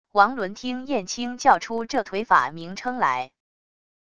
王伦听燕青叫出这腿法名称来wav音频生成系统WAV Audio Player